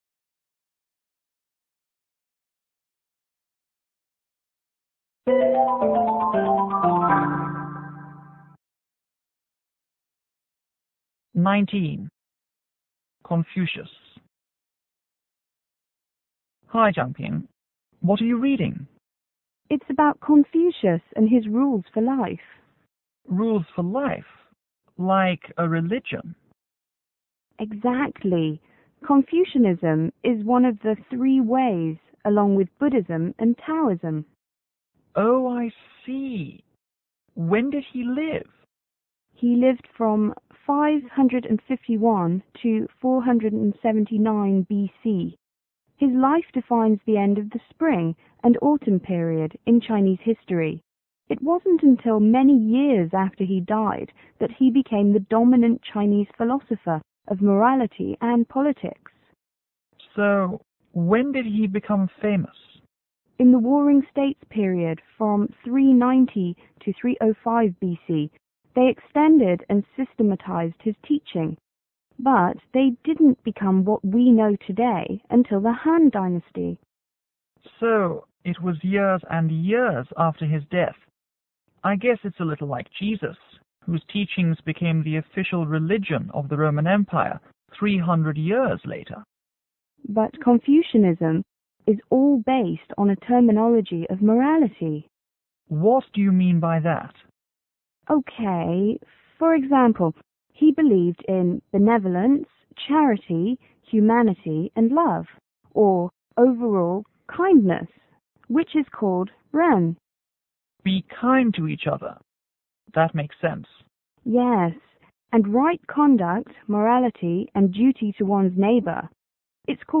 FS: Foreign student    S: Student